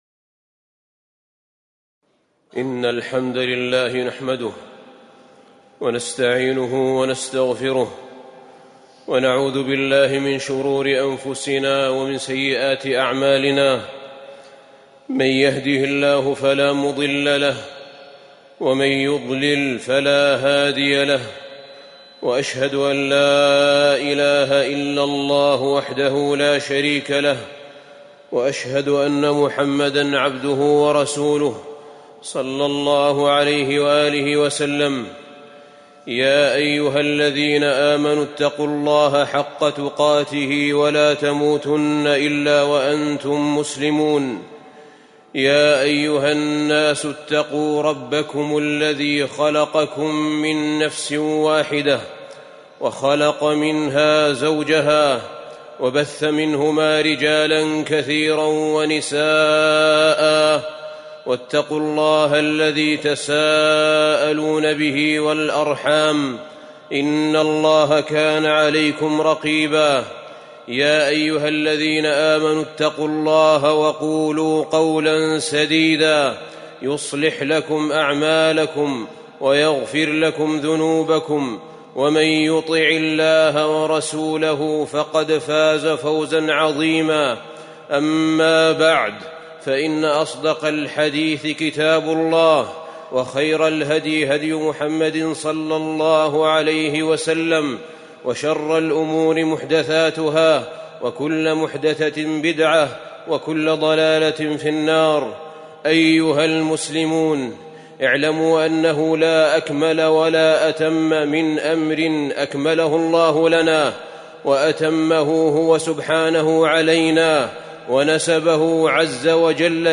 خطبة فضل دين الإسلام ووجوب الدخول فيه على جميع الأنام وفيها: أنه دين كامل تام، وأن الله أخذ ميثاق النبيين على إتباع نبي الله محمد عليه الصلاة والسلام
تاريخ النشر ٦ جمادى الآخرة ١٤٤١ هـ العقيدة المكان: المسجد النبوي الشيخ: فضيلة الشيخ أحمد بن طالب حميد فضيلة الشيخ أحمد بن طالب حميد فضل دين الإسلام ووجوب الدخول فيه على جميع الأنام